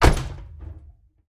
car-door-close-2.ogg